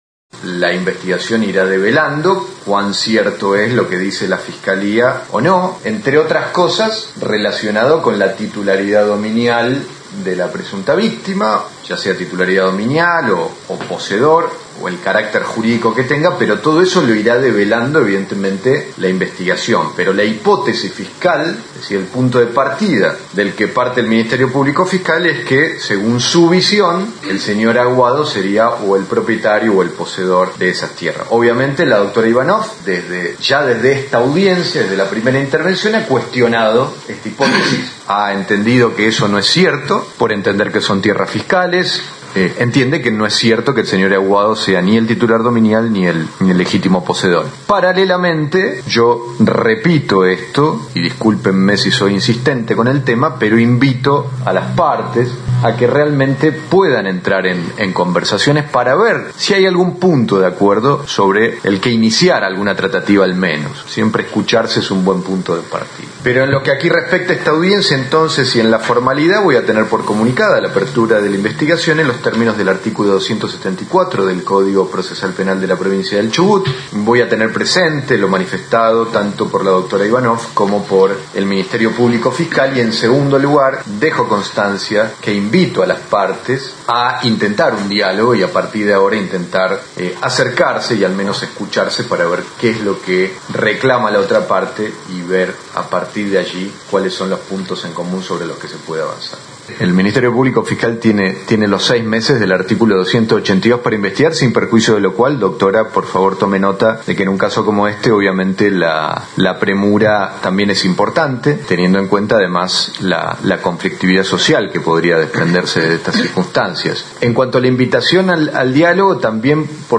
En los tribunales de Esquel se realizó la audiencia de apertura de investigación, donde el Ministerio Público Fiscal acusa por el delito de usurpación a varios integrantes de la Comunidad Mapuche Nahuelpan. En la audiencia presidida por el Juez Penal Martín O´Connor, la Fiscal Mónica Caveri relató el hecho por el cual se acusa a los integrantes de la comunidad Mapuche.